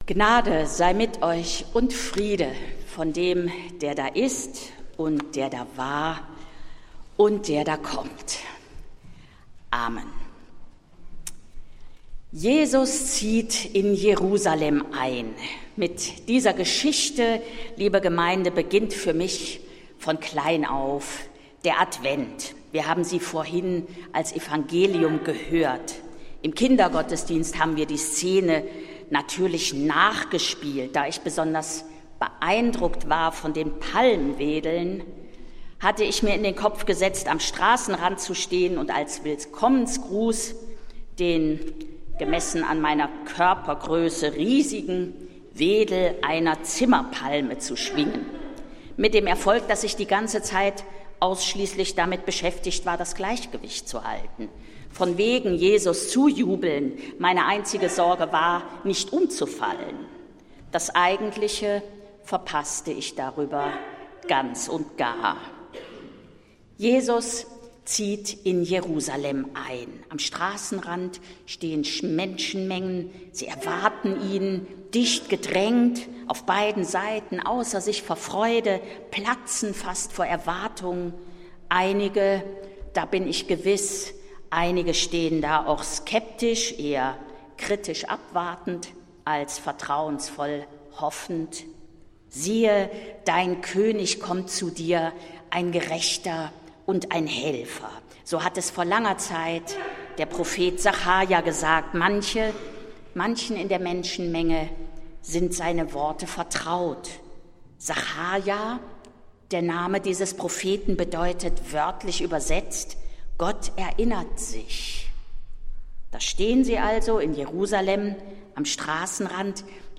Predigt des Gottesdienstes aus der Zionskirche vom Sonntag, 30. November 2025
Die Predigt des Gottesdienstes an diesem Sonntag hielt Präses a. D., Dr. h. c. Annette Kurschus.